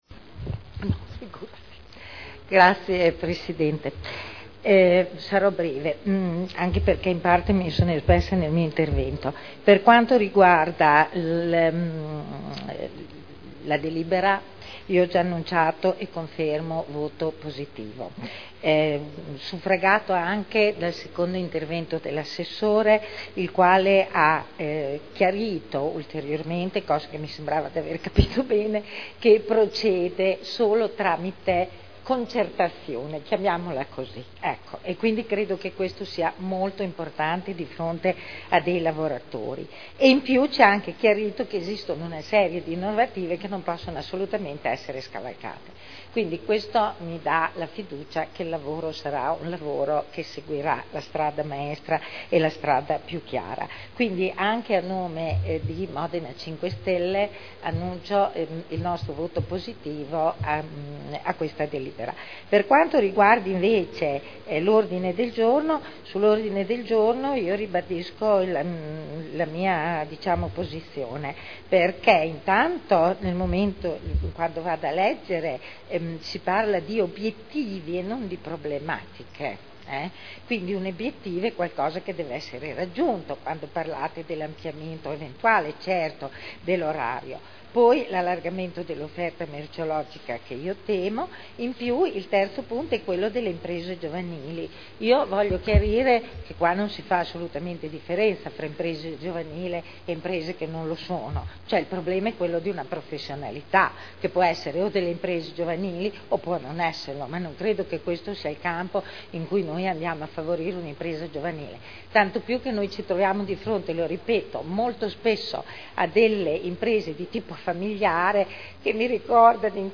Eugenia Rossi — Sito Audio Consiglio Comunale
Regolamento comunale del mercato coperto quotidiano di generi alimentari denominato “Mercato Albinelli” – Approvazione modifiche Dichiarazione di voto